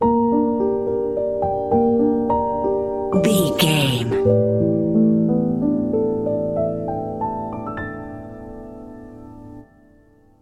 Ionian/Major
piano
contemplative
dreamy
quiet
tranquil
haunting
melancholy
mystical
ethereal